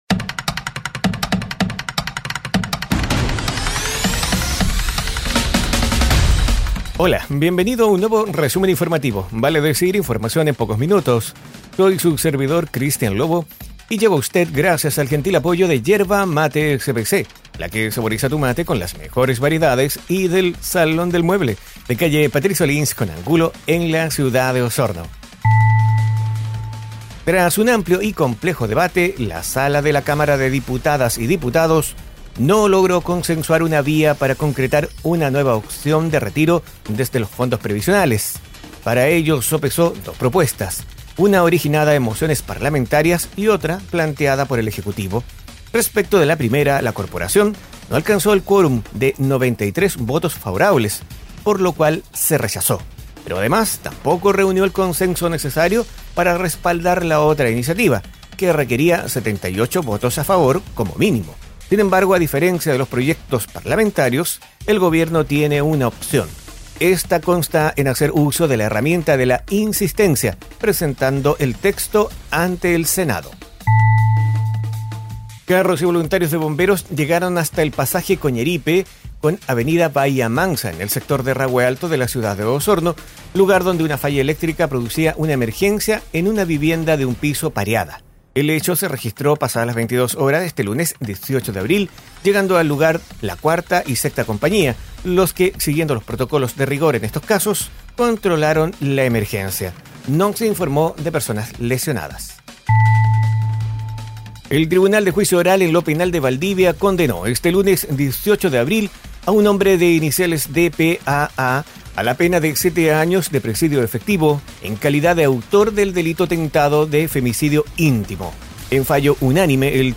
Resumen Informativo ▶ Podcast 19 de abril de 2022